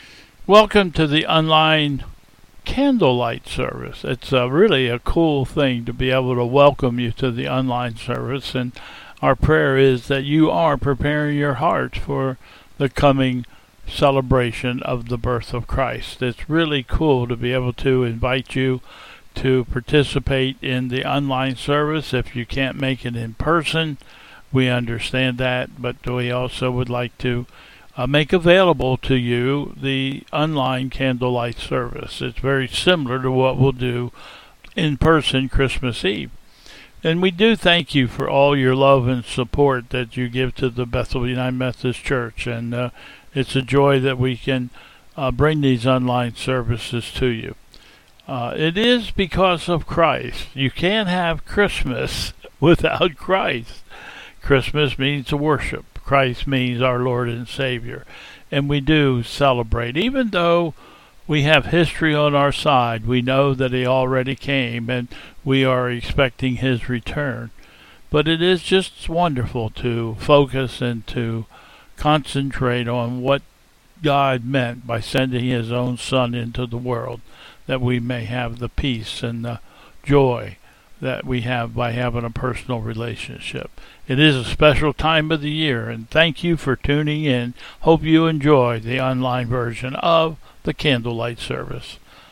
. Welcome to the Candlelight Service